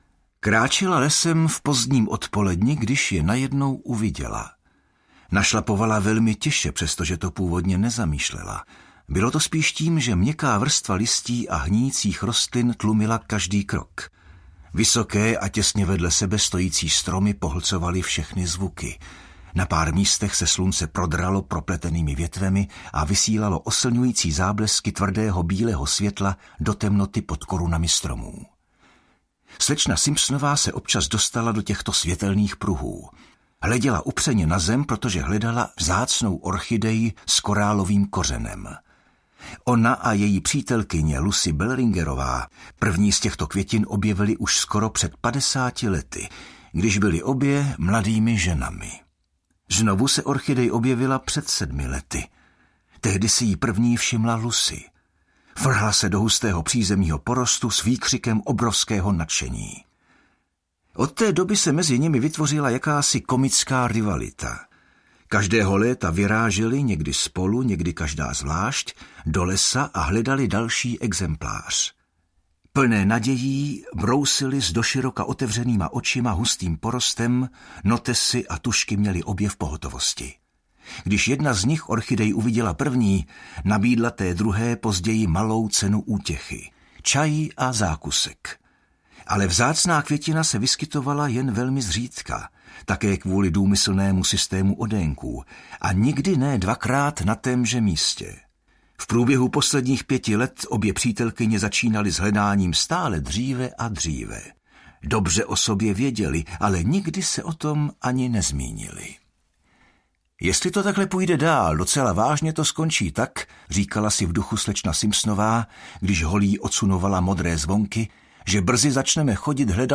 Mrtví v Badger’s Drift audiokniha
Ukázka z knihy